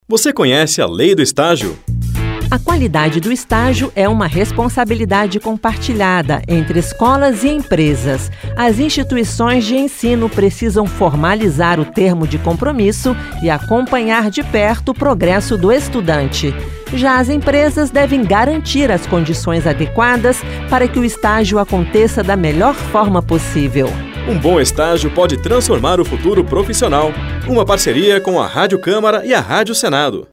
Esta campanha da Rádio Câmara e da Rádio Senado traz cinco spots de 30 segundos sobre a Lei do Estágio: seus direitos, obrigações e os principais pontos da lei.